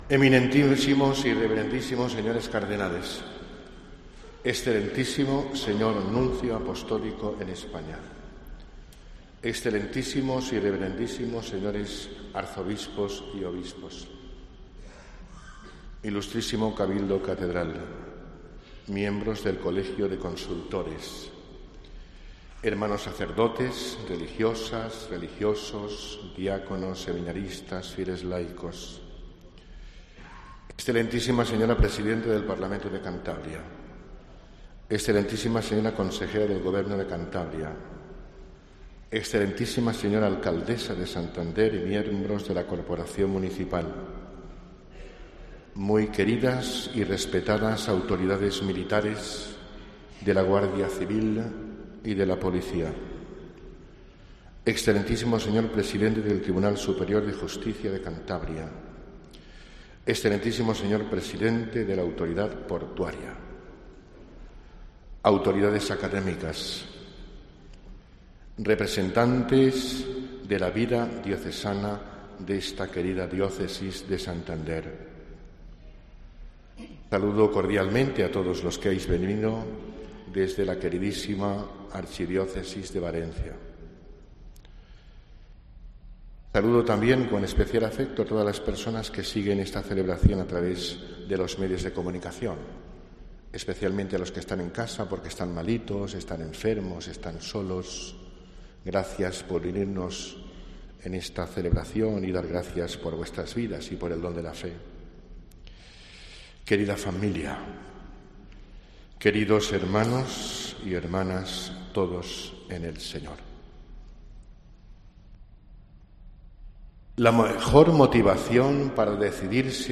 Homilía del Obispo de Santander, Arturo Ros
Una Catedral abarrotada de fieles y autoridades ha acogido la misa solemne del inicio del Ministerio Episcopal de Arturo Ros Murgadas como Obispo de la Diócesis de Santander.